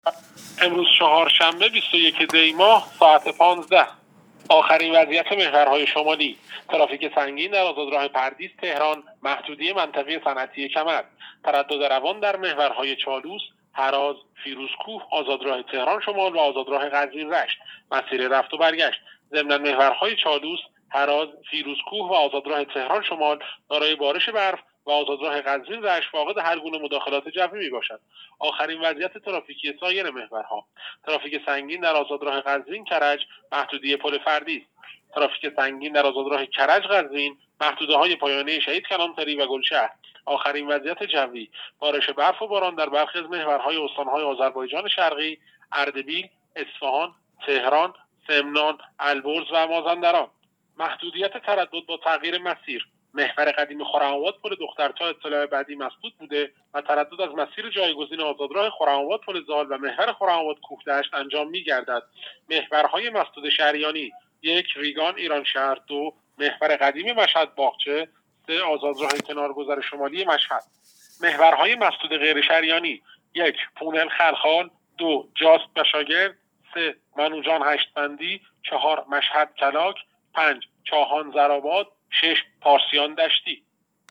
گزارش رادیو اینترنتی از آخرین وضعیت ترافیکی جاده‌ها تا ساعت ۱۵ بیست و یکم دی؛